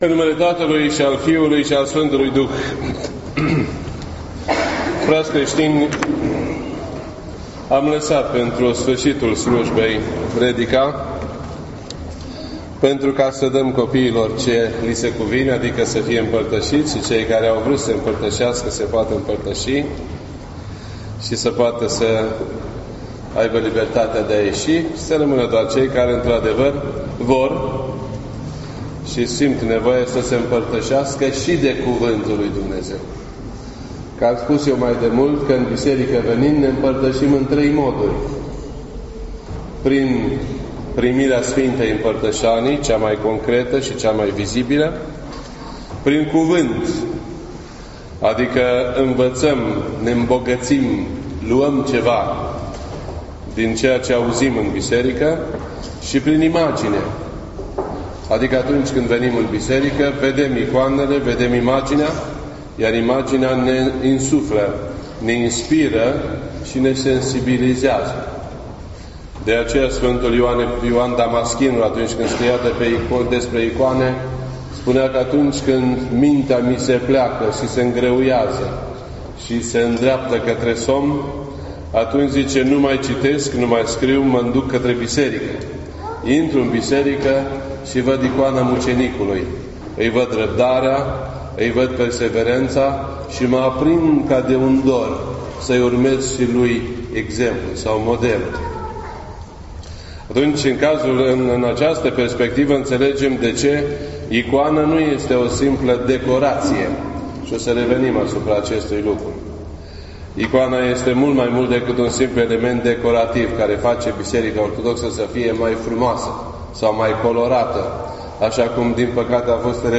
This entry was posted on Sunday, June 18th, 2017 at 6:45 PM and is filed under Predici ortodoxe in format audio.